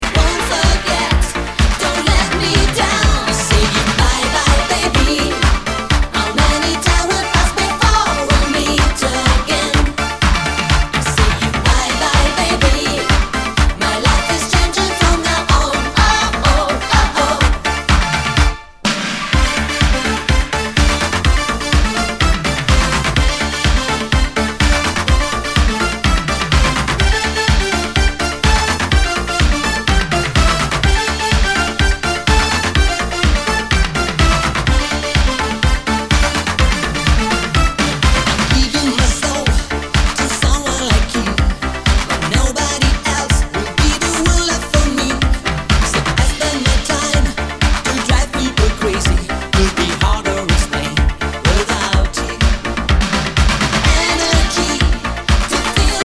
類別 歐陸舞曲
HOUSE/TECHNO/ELECTRO